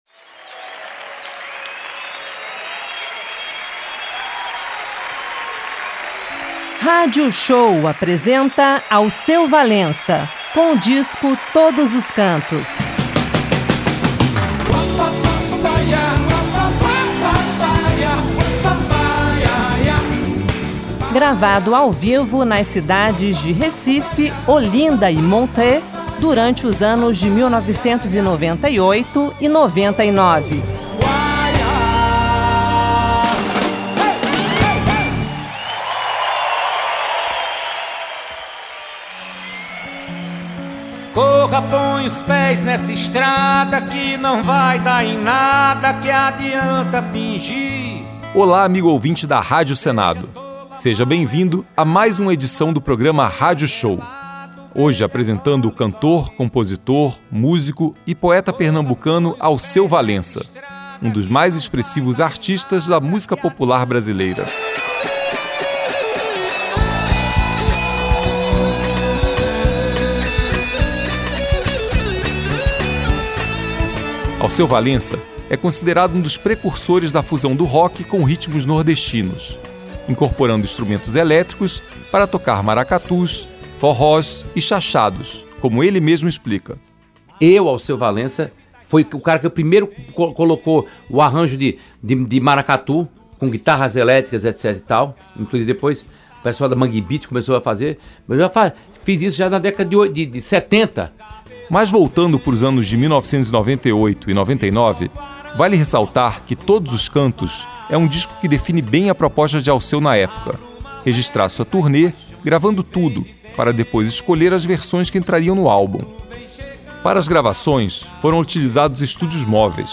gravado ao vivo nas cidades de Recife, Olinda e Montreux
sanfona
baixo
guitarras e viola
bateria
percussão
teclados
violoncelo
flautas e sax
MPB Frevo Forró Pop Pop rock Ritmos nordestinos